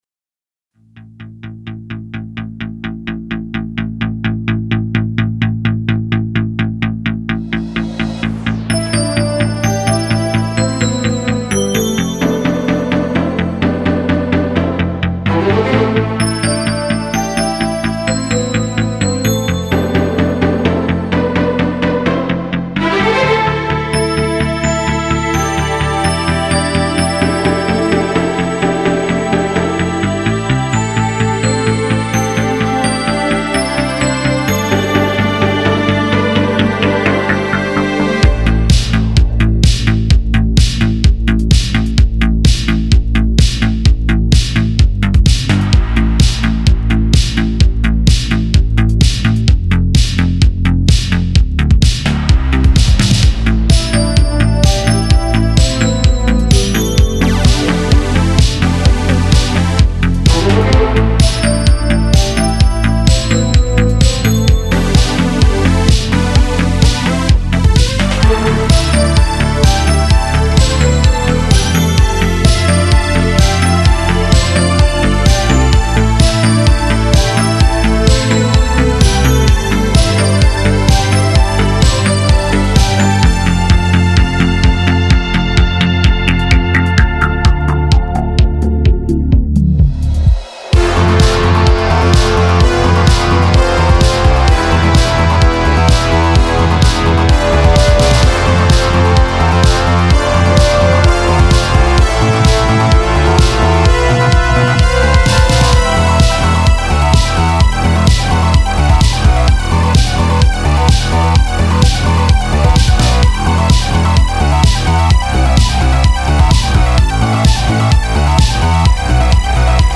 Genre: Synthpop Syntwave.